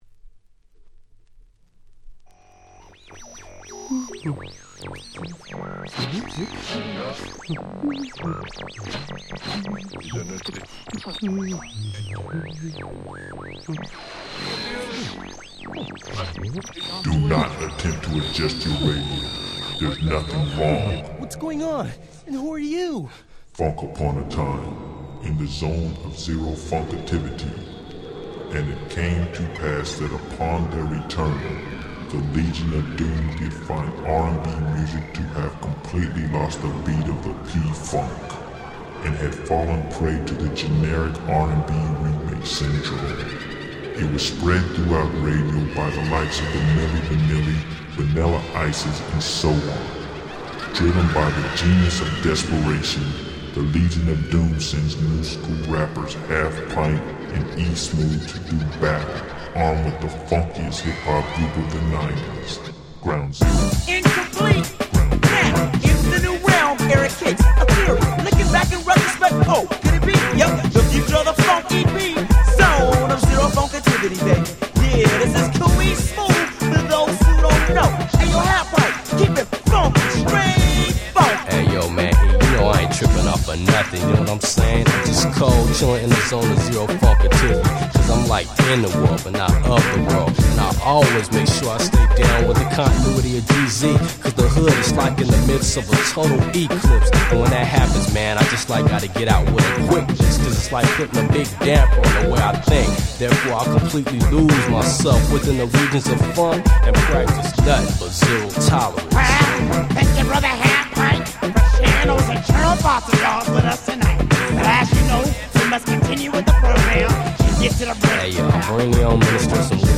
91' Smash Hit West Coast Hip Hop !!
グラウンドゼロ 90's ウエスト ウエッサイ Boom Bap ブーンバップ